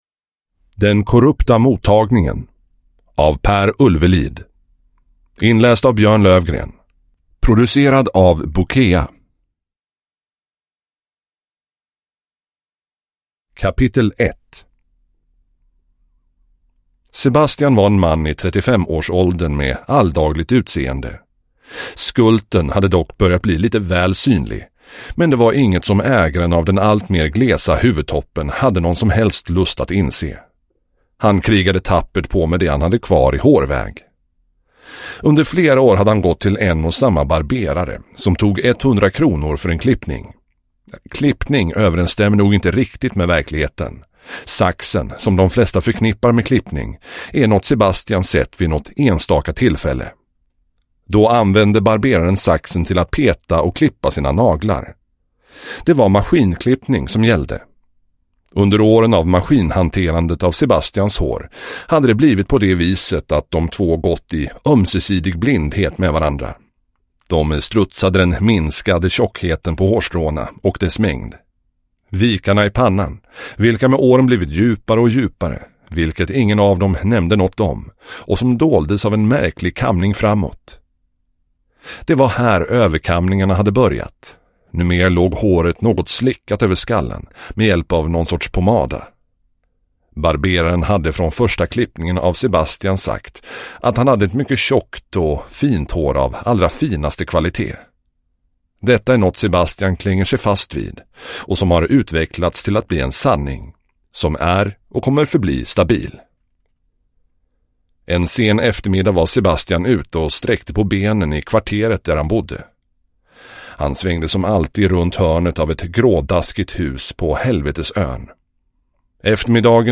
Den korrupta mottagningen – Ljudbok